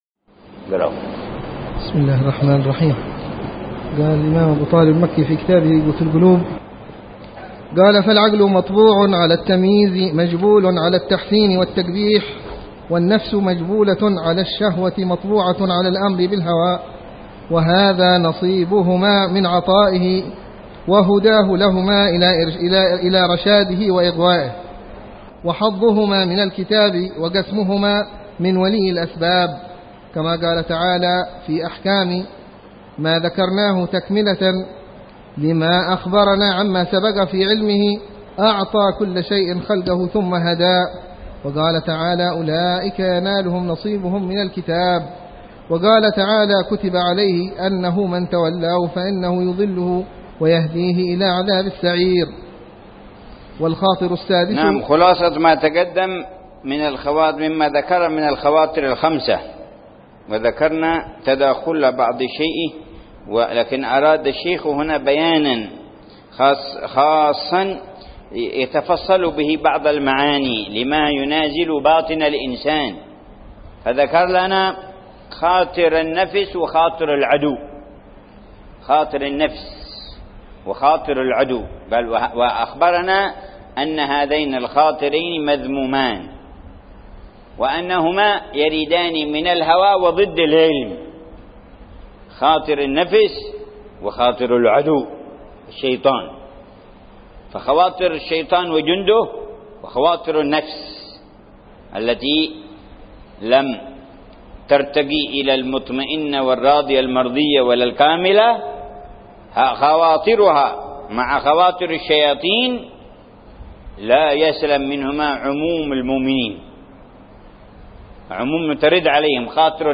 شرح كتاب قوت القلوب - الدرس السادس والثلاثون
قراءة بتأمل وشرح لمعاني كتاب قوت القلوب للشيخ: أبي طالب المكي ضمن دروس الدورة التعليمية السادسة عشرة بدار المصطفى 1431هجرية.